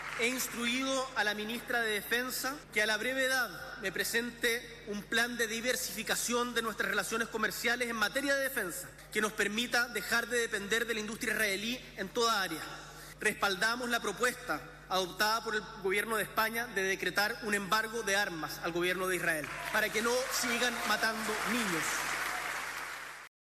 cuna-tl-discurso-boric-israel.mp3